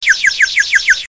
clock11.ogg